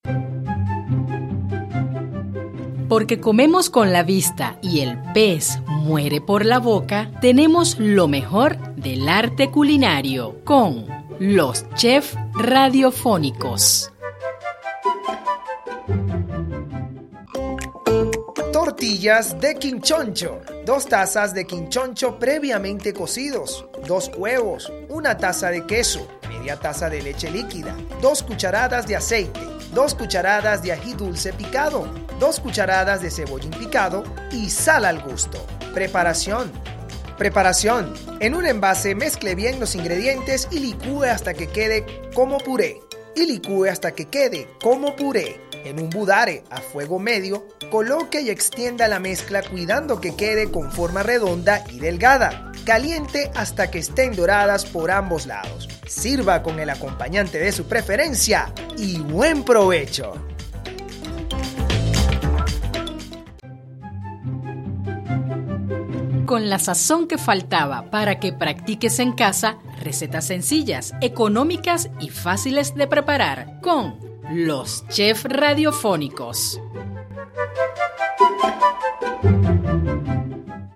Toma nota de esta nueva opción que te presentamos también en formato de micro radial.